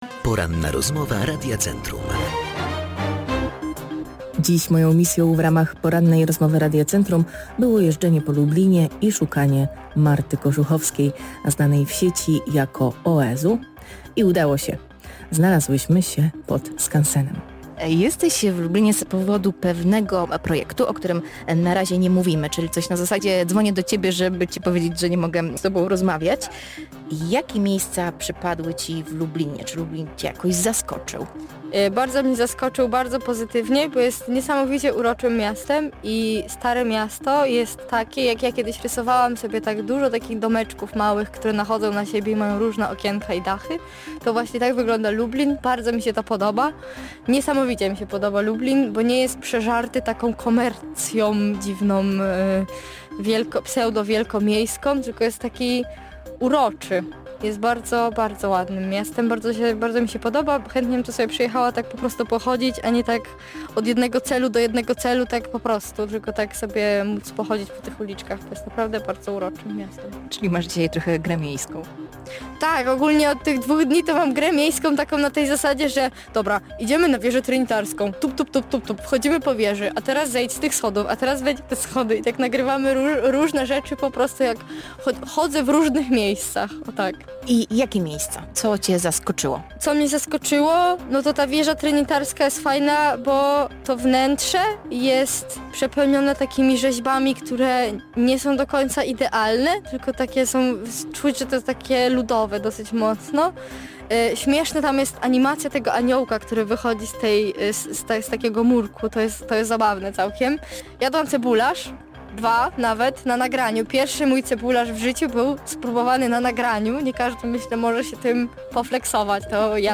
Influencerka opowiedziała w programie między innymi o swojej wizycie w Lublinie i wytłumaczyła czym jest tak zwany lubelski pocałunek. W rozmowie poruszono również temat jak twórcy internetowi radzą sobie z przebodźcowaniem związanym z ekranami i Internetem.